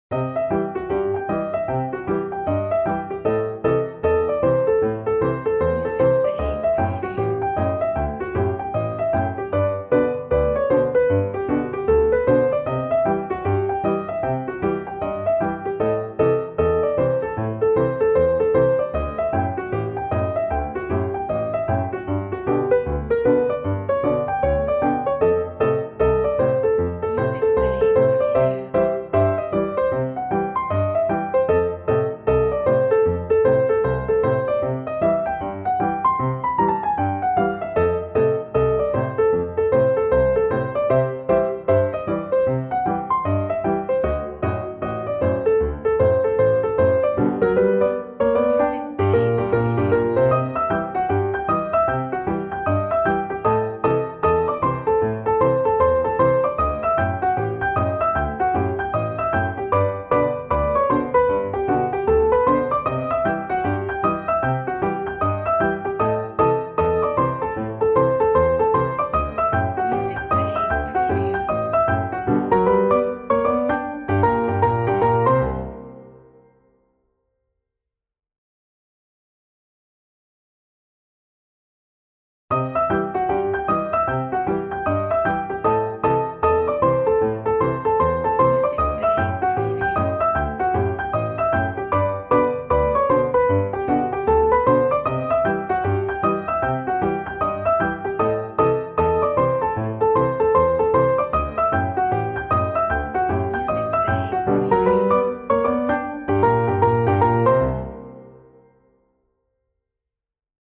Funny music background.